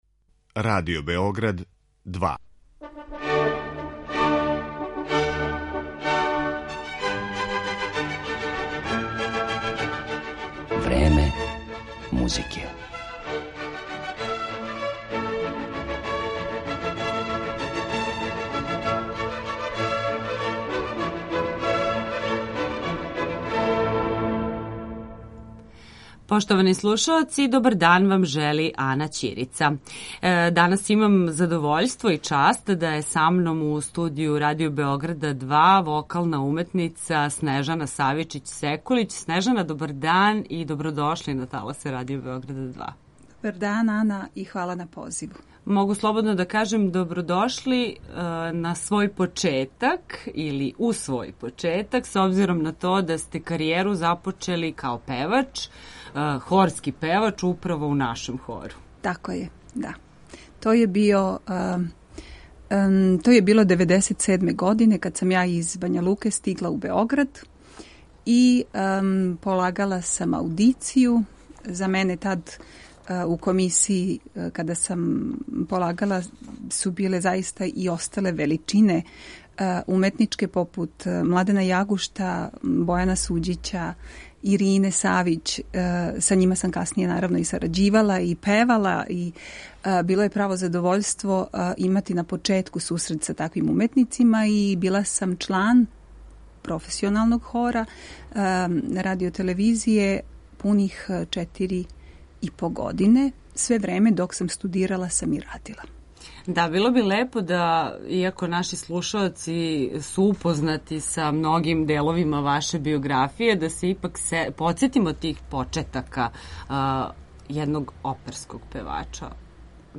Гошћа Времена музике